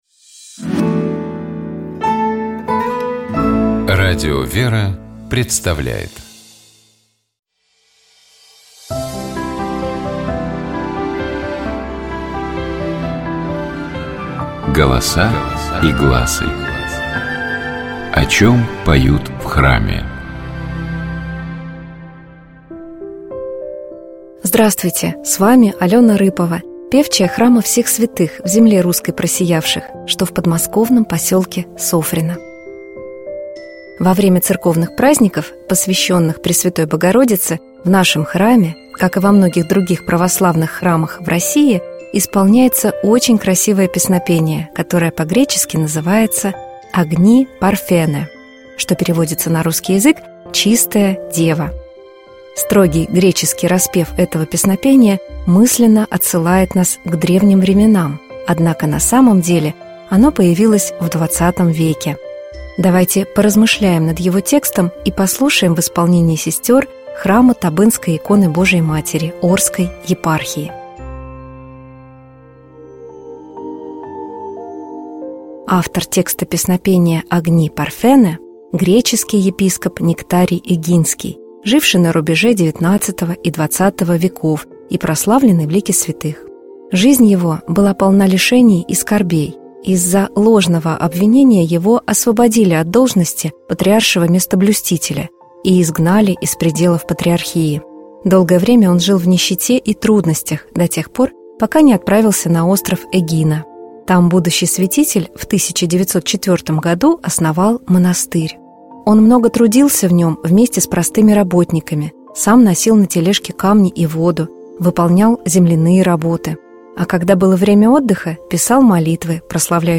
Во время церковных праздников, посвящённых Пресвятой Богородице, в нашем храме, как и во многих других православных храмах в России, исполняется очень красивое песнопение, которое по-гречески называется «Агни Парфене», что переводится на русский язык «Чистая Дева». Строгий греческий распев этого песнопения мысленно отсылает нас к древним временам, однако на самом деле оно появилось в XX веке. Давайте поразмышляем над его текстом и послушаем в исполнении сестёр храма Табынской иконы Божией Матери Орской епархии.